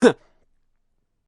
Uh Hesitate Before Sneeze
SFX
Uh   Hesitate Before Sneeze.mp3